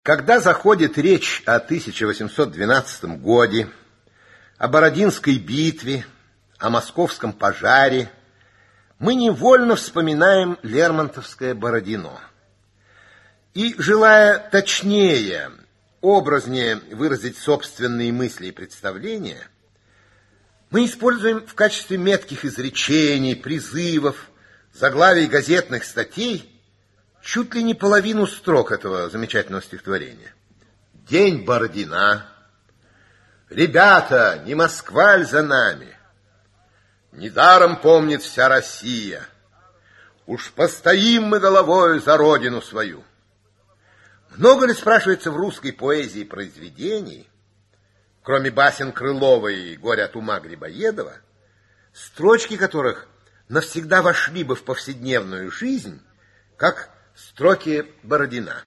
Аудиокнига Бородино | Библиотека аудиокниг
Aудиокнига Бородино Автор Ираклий Андроников Читает аудиокнигу Ираклий Андроников.